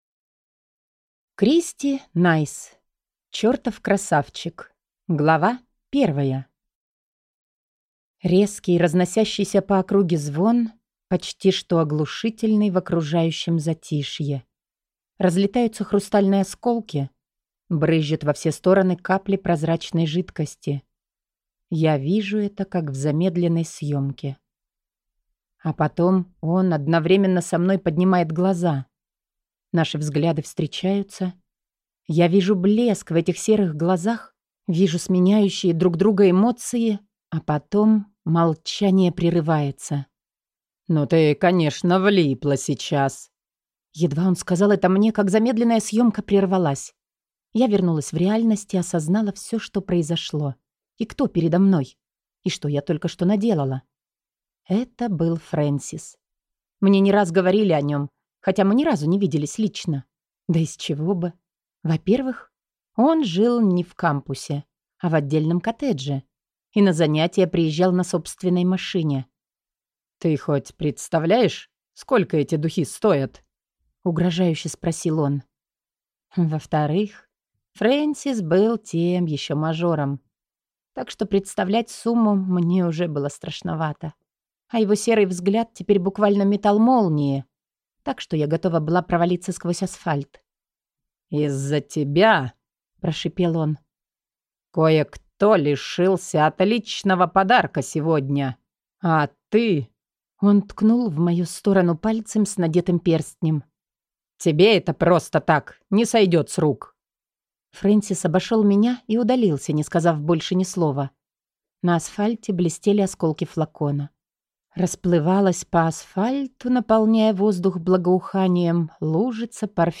Аудиокнига Чертов красавчик | Библиотека аудиокниг